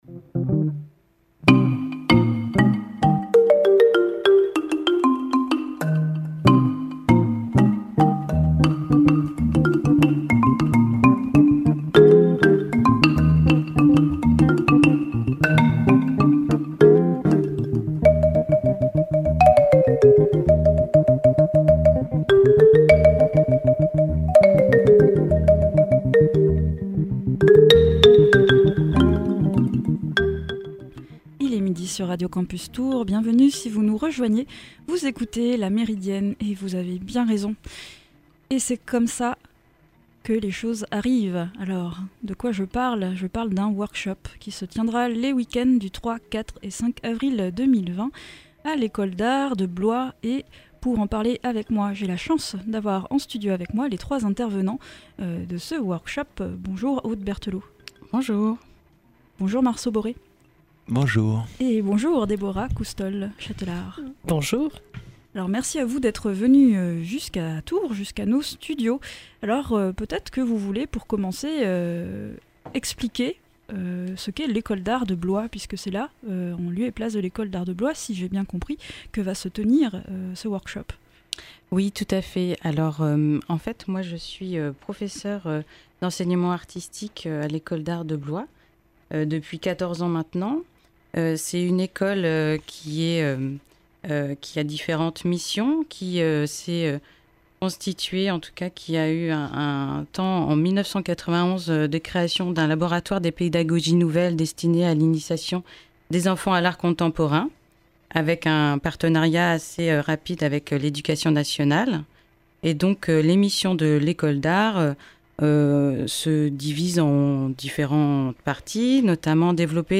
Les 3, 4, 5 avril 2020 se tiendra à l’Ecole d’art de Blois un workshop autour du son. Les trois intervenants nous présentent l’évenement, et nous proposent des extraits sonores.